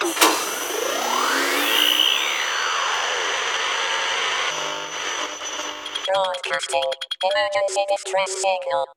Radio_fix.ogg